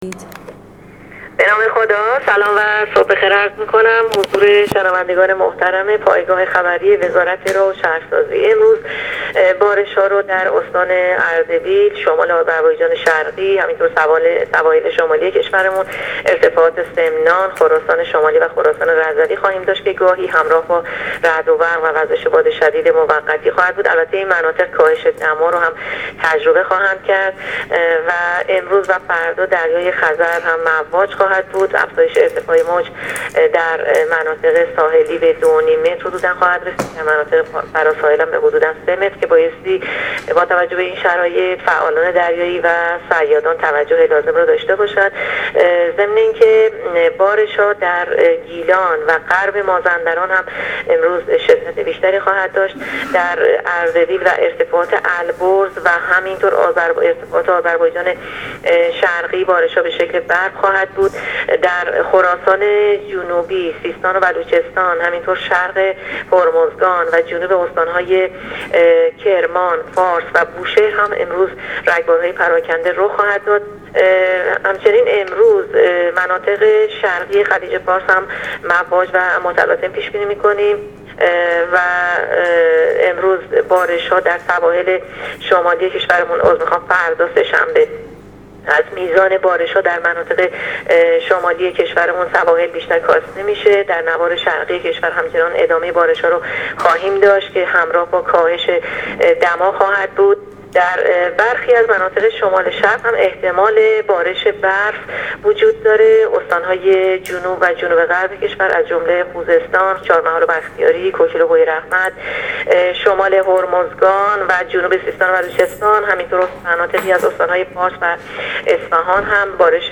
گزارش رادیو اینترنتی پایگاه خبری از آخرین وضعیت آب‌وهوای ۱۶ آبان؛